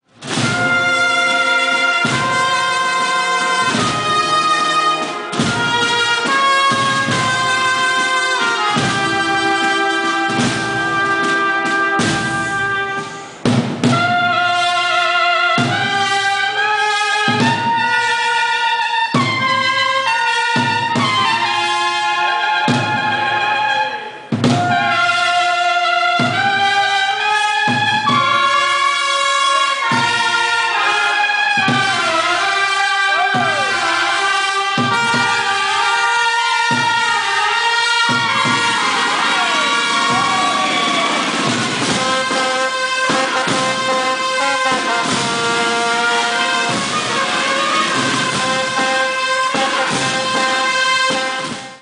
Bandas musicales
Agrupación musical Virgen de los Dolores